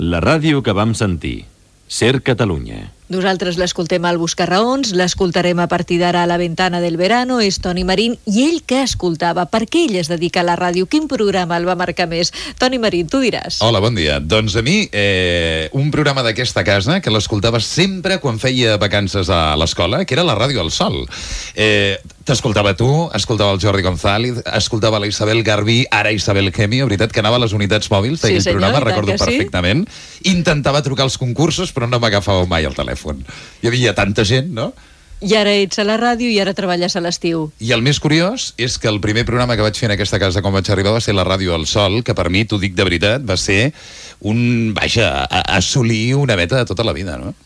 Divulgació